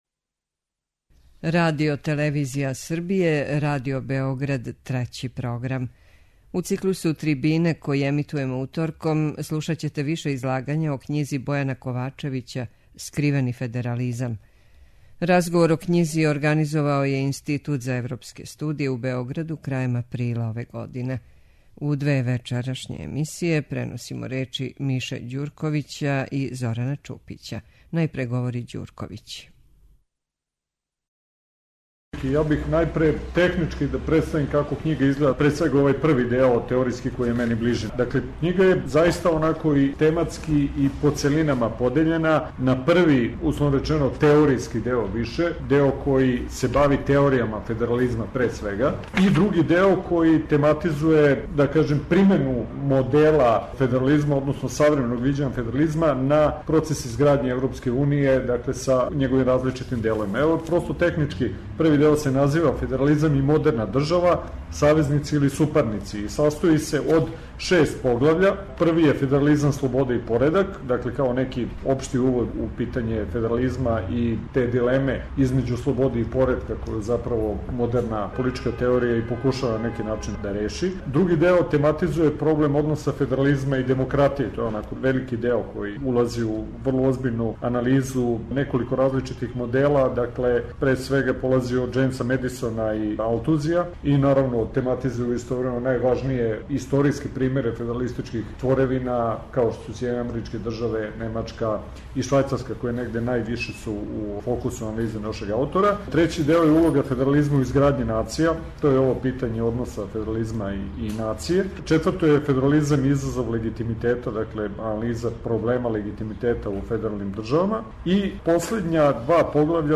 Разговор о књизи организовао је Институт за европске студије у Београду крајем априла ове године.